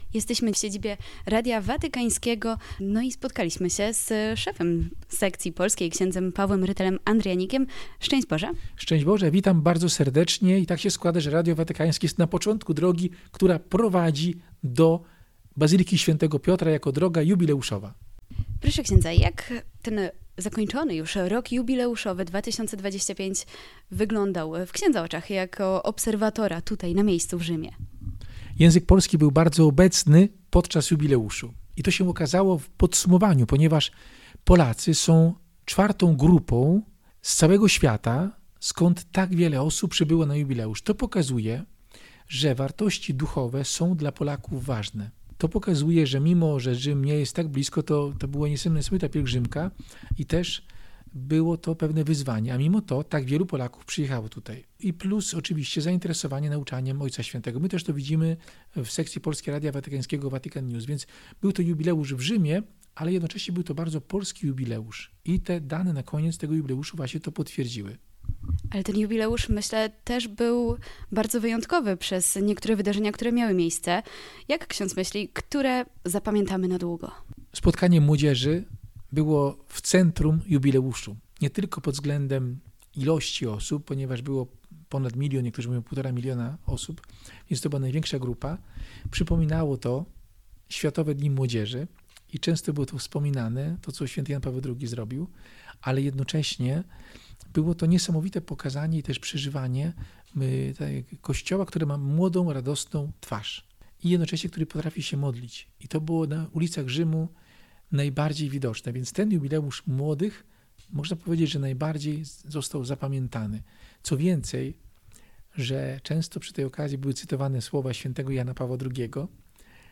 Rozmowy, które podczas naszego pobytu w Watykanie przeprowadziliśmy z uczestnikami zakończenia Roku Jubileuszowego pokazują, że był to czas głębokiego duchowego doświadczenia, odnowy serca i umocnienia wiary – zarówno osobistej, jak i wspólnotowej.